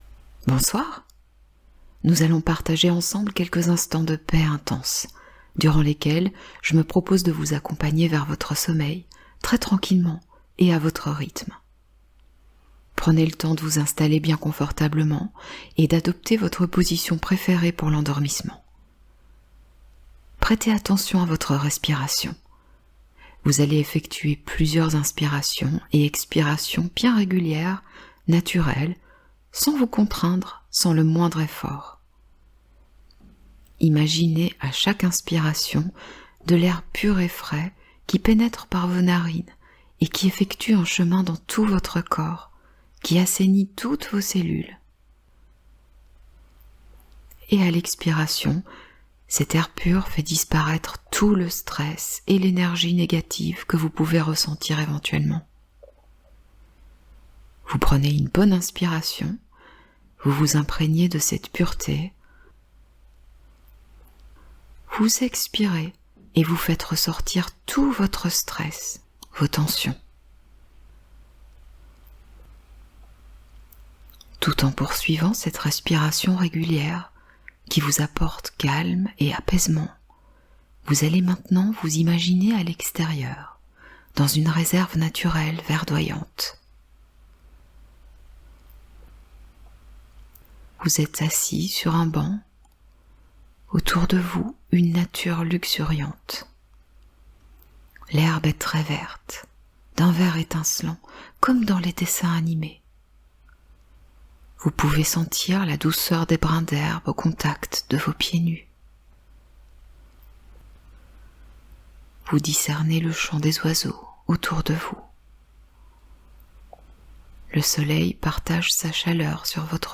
ASMR Hypnose : Sommeil parfait + Reprogrammez votre esprit pendant la nuit ★ Paix totale